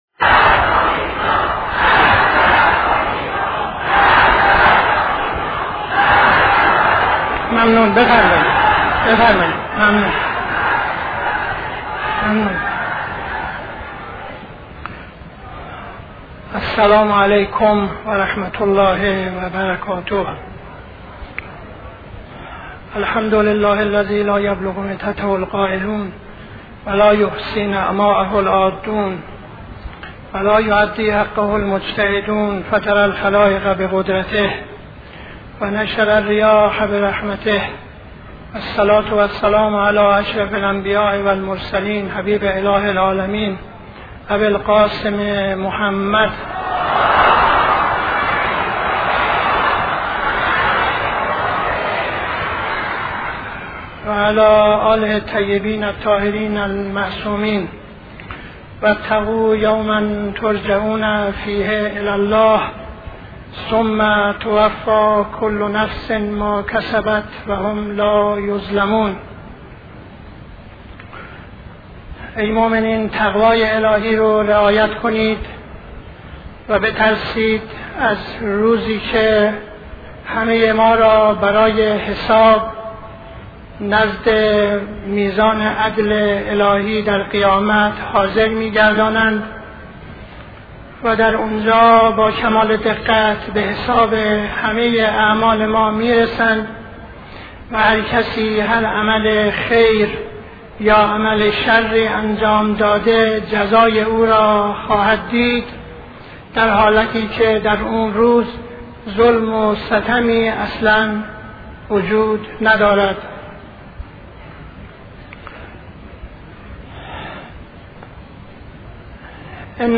خطبه اول نماز جمعه 19-09-72